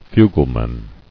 [fu·gle·man]